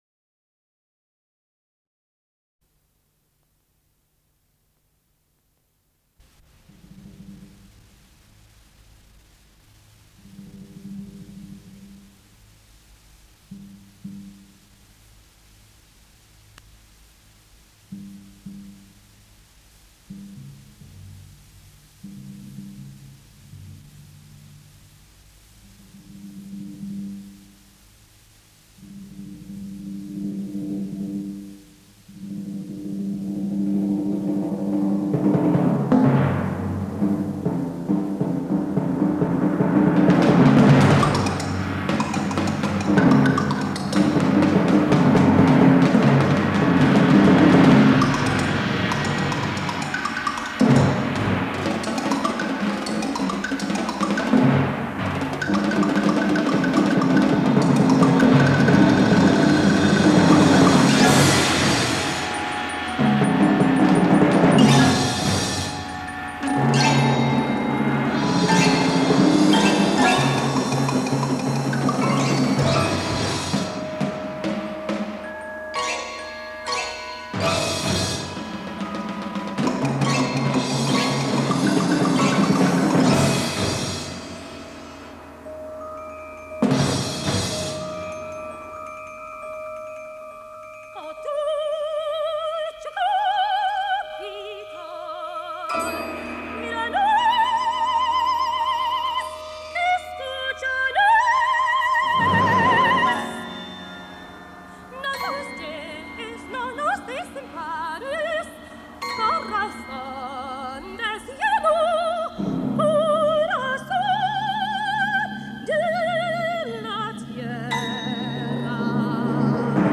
destacando la fuerza coral
[Casete].